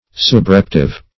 Subreptive \Sub*rep"tive\